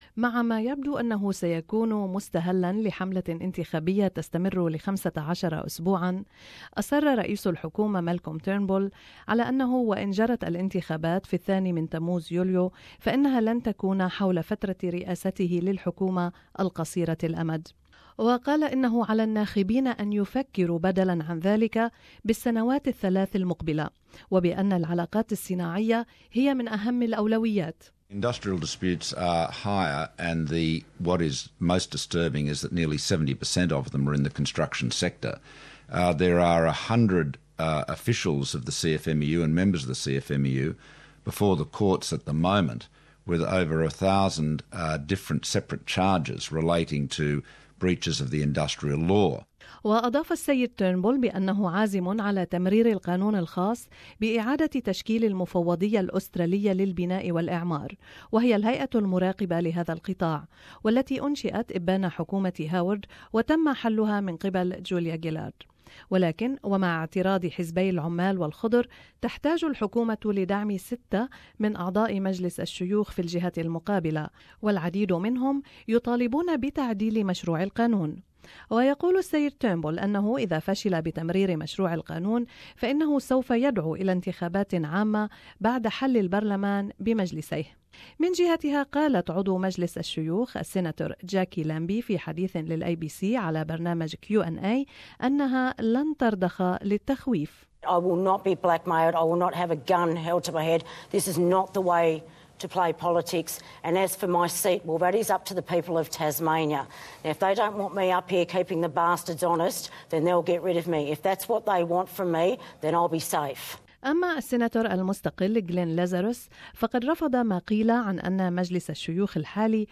المزيد في هذا التقرير